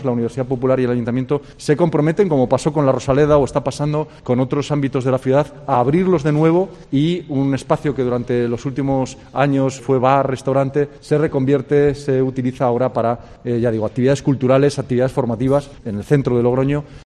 Pablo Hermoso de Mendoza, alcalde de Logroño